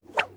swish_2.wav